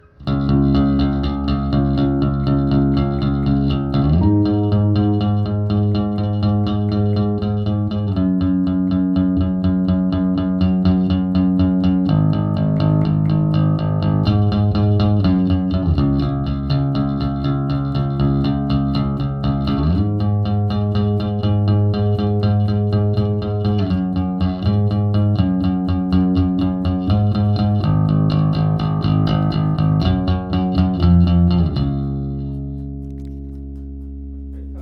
viel schnalzen, solider bass und genügend mitten, denke ich. vol 100%, treble 100%, bass 50% gespielt mit pick in einen ampeg v4b und zwei geschlossene 410er von fmc, saiten sind lo rider steels.
ich mag echt gern, wie sich das so schön knallig durch den mix frisst, fein!